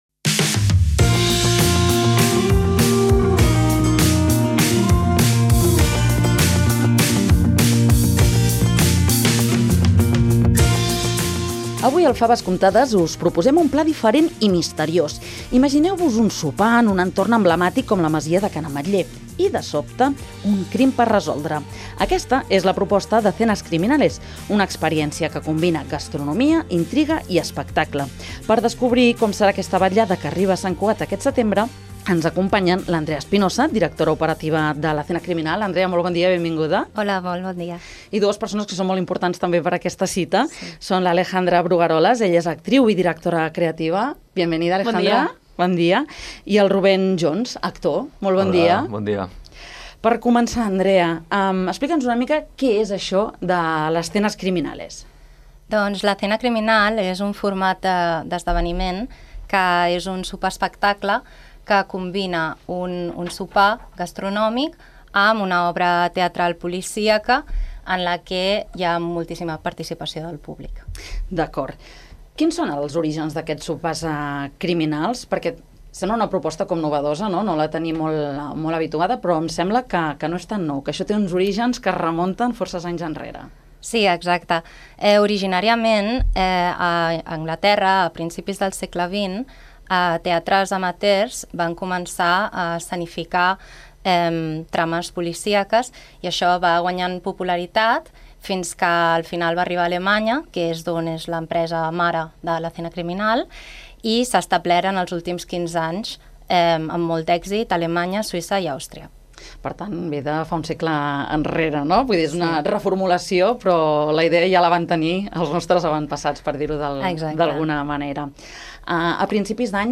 La entrevista de la semana con Denominación de Origen